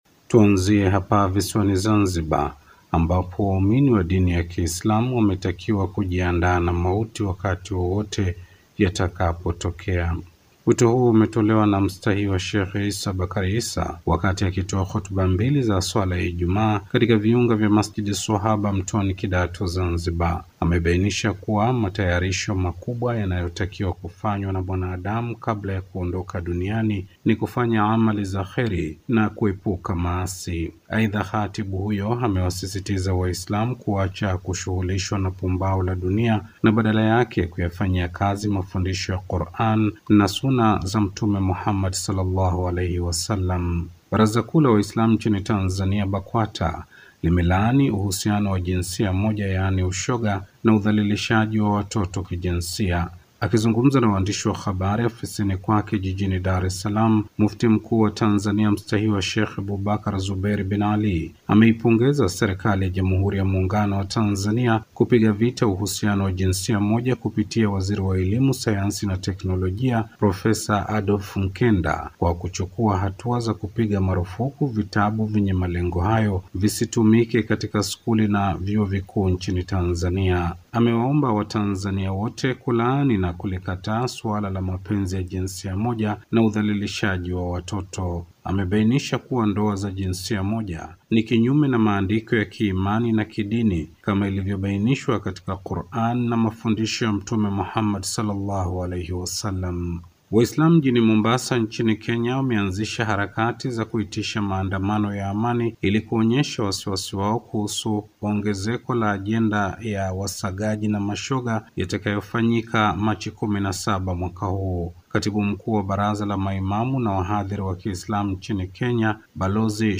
Ingia kwenye Sauti kusikiliza ripoti hiyo iliyo na matukio tofauti ya Kiislamu wiki hii.